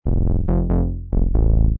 醇厚的低音
描述：醇厚的低音电子恍惚舞曲
Tag: 140 bpm Electronic Loops Bass Synth Loops 295.48 KB wav Key : Unknown